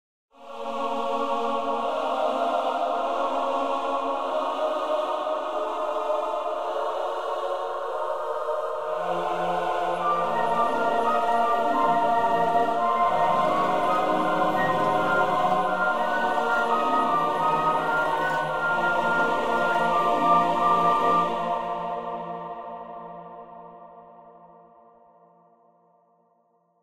soundtrack/game music